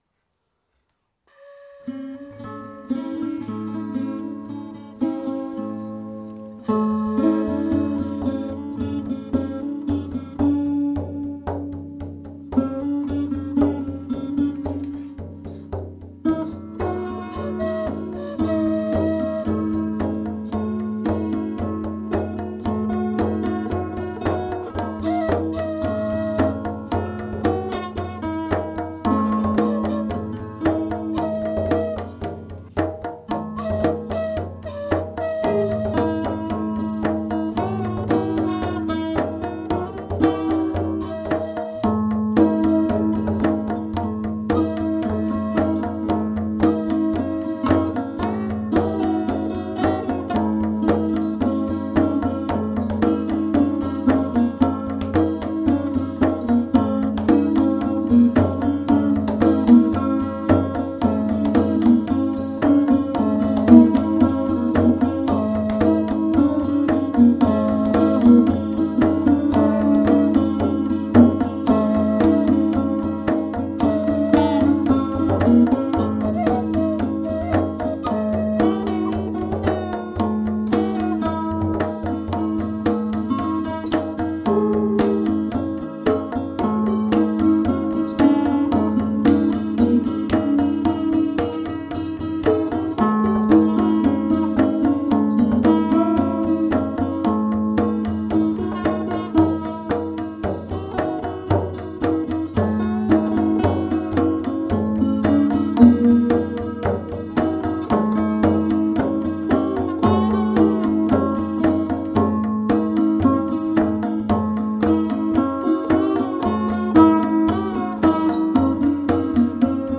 Dzikus is meditative soundscape; there is a djembe rhytm, two 12-string guitar tracks and flute track.